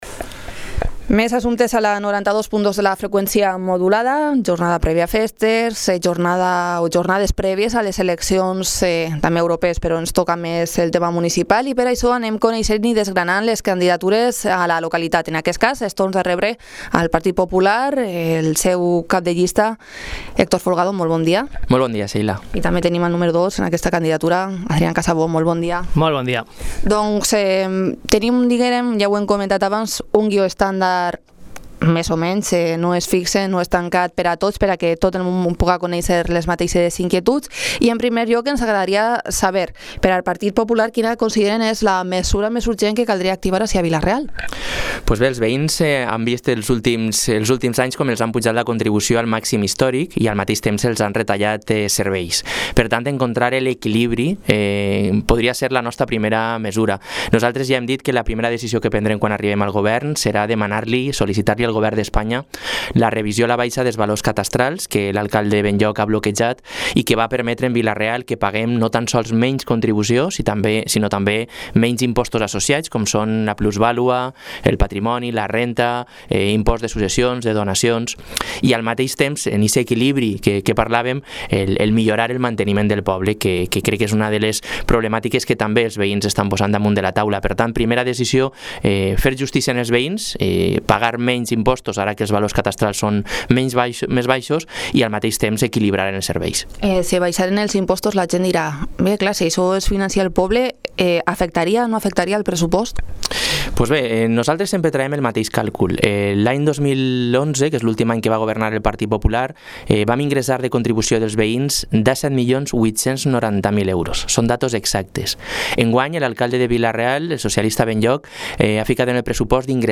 Entrevista política, PP.